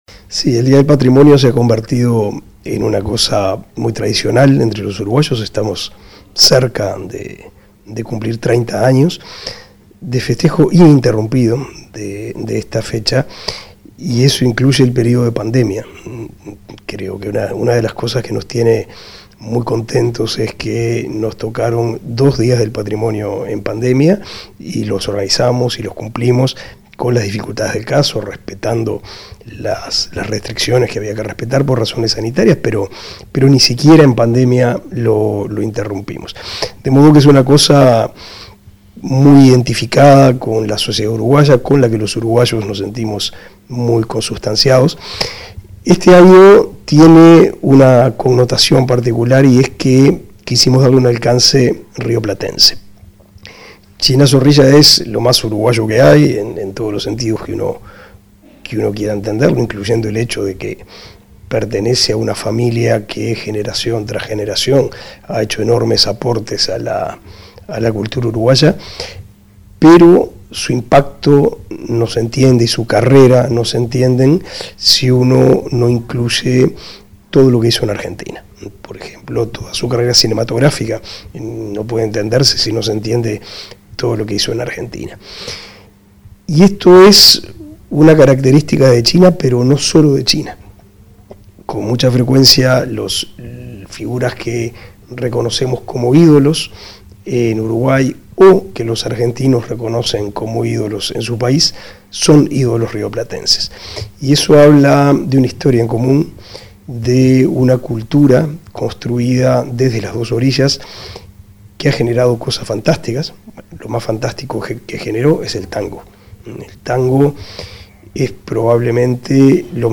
Entrevista al ministro de Educación y Cultura, Pablo da Silveira